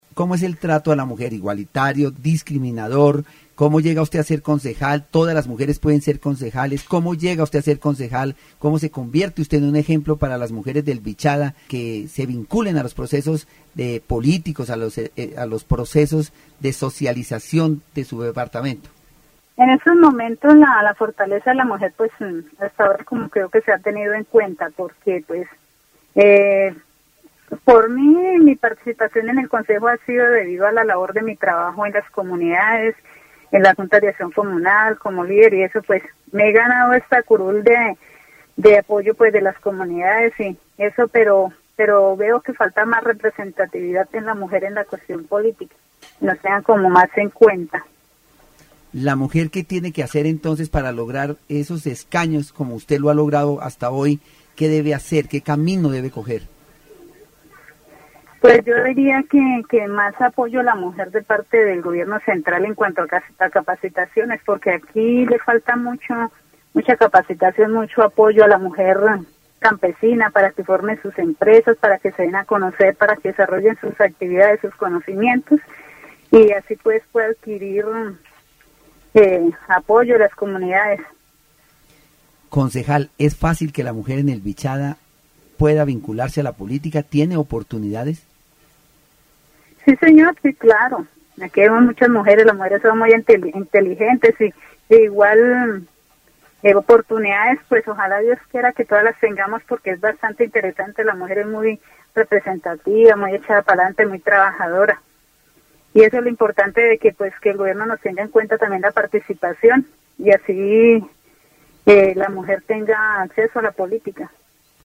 Interview on women's participation in politics in Vichada, highlighting the lack of female representation and the need for greater government support for women's training and empowerment.
Entrevista sobre la participación de la mujer en la política en Vichada, destacando la falta de representatividad femenina y la necesidad de un mayor apoyo gubernamental para la capacitación y el empoderamiento de las mujeres.